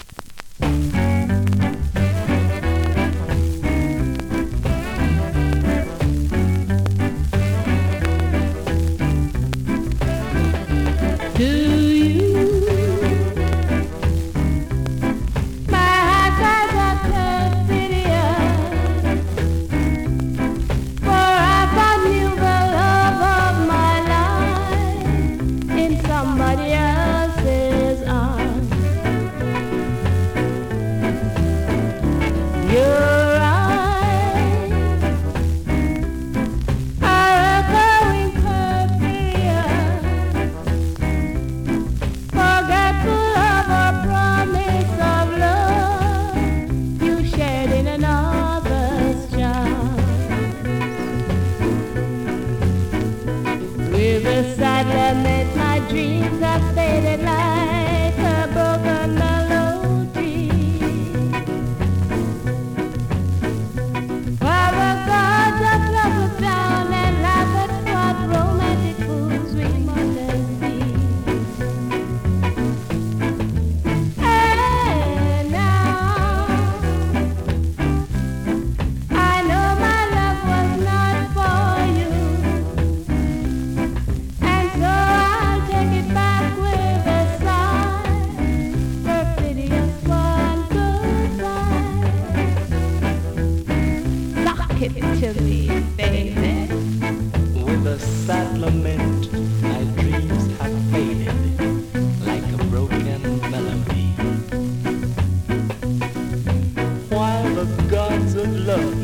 うすくノイズとエッジワープ有)   コメントレアROCKSTEADY!!
スリキズ、ノイズ比較的少なめで